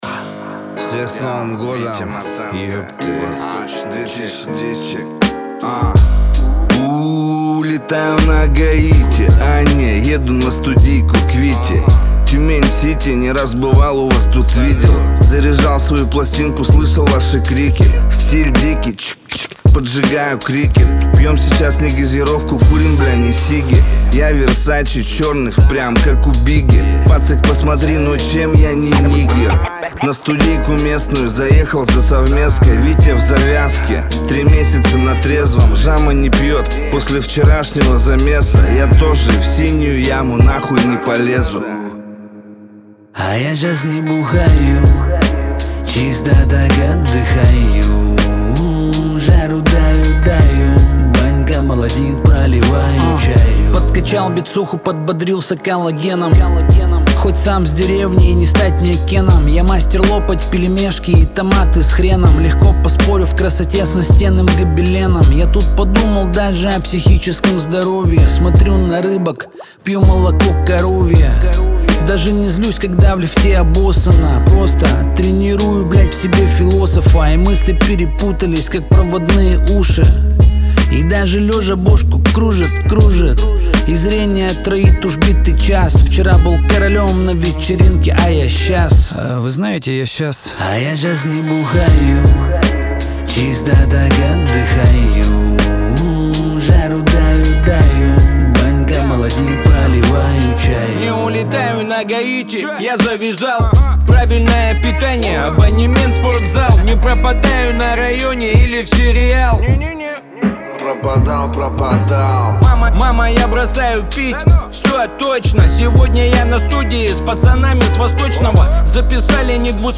Трек размещён в разделе Рэп и хип-хоп / Русские песни.